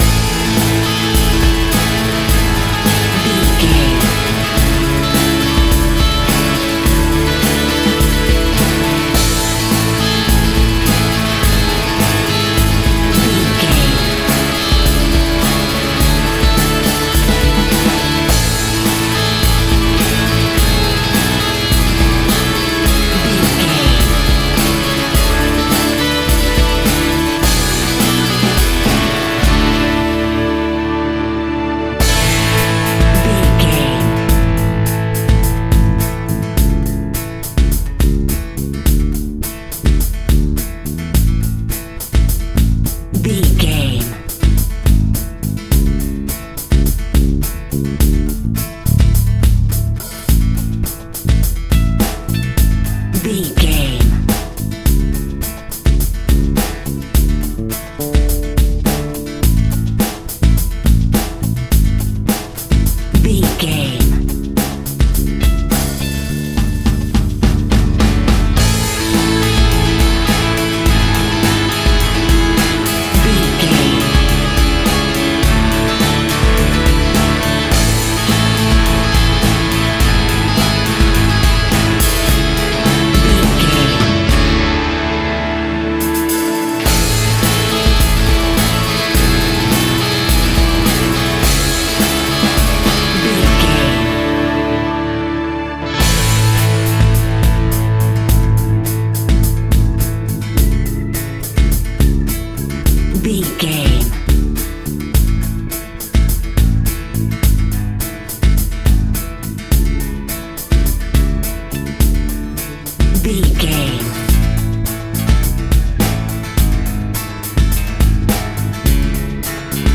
Ionian/Major
energetic
uplifting
drums
bass guitar
piano
hammond organ
synth